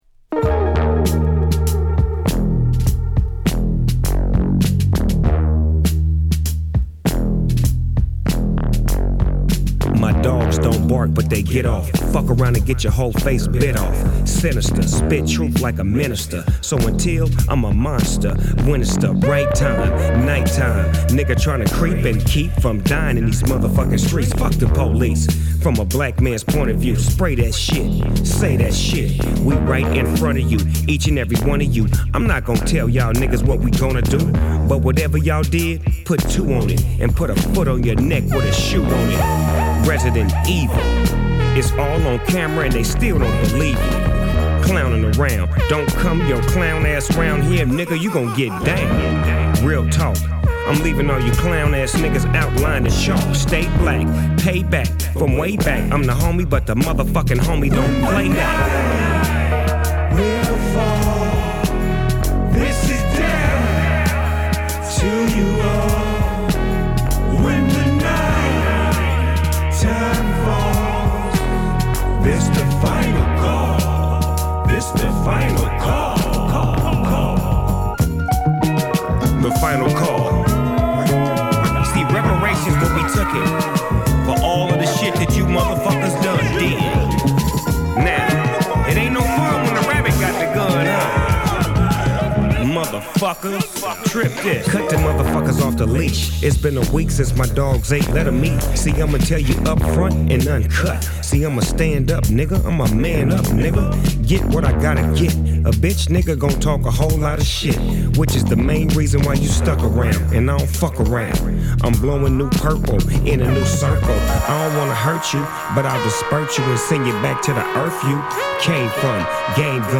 12)Hip Hop / R&B
霧がかったアブストラクトなビートに切れ味鋭いラップをたたみかけたルードな楽曲。...